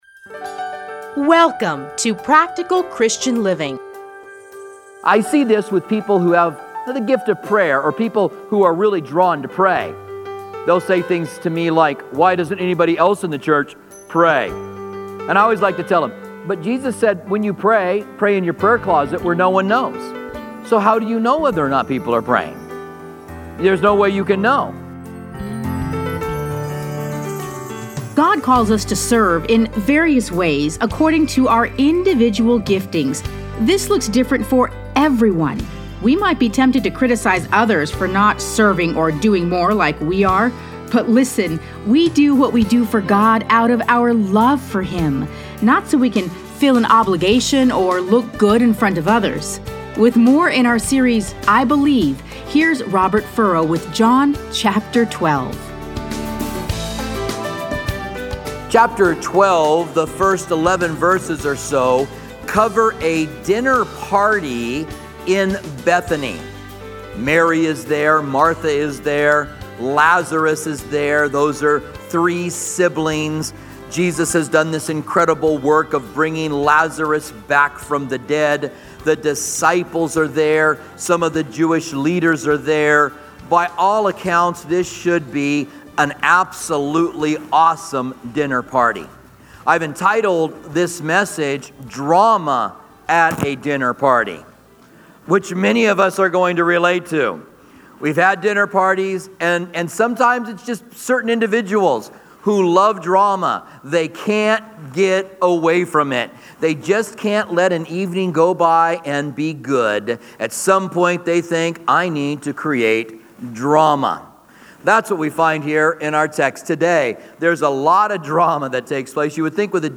Listen to a teaching from John 12:1-12.